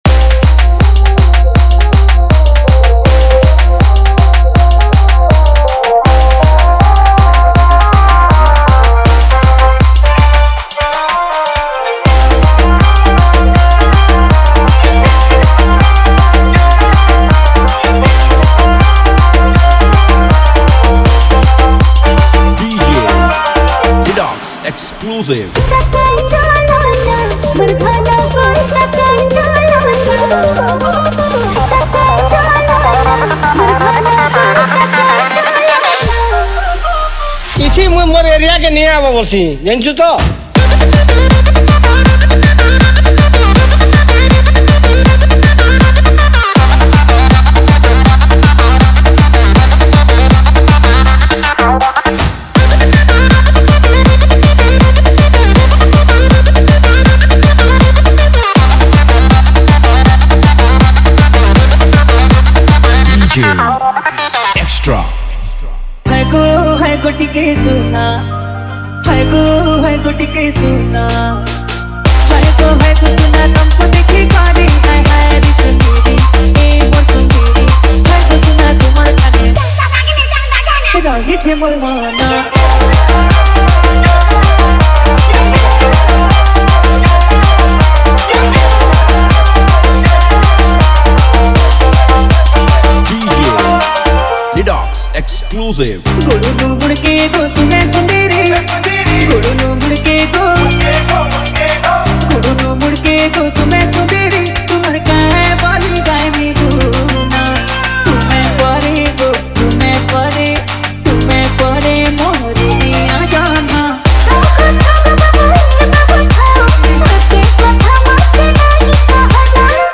Category:  Sambalpuri Dj Song 2026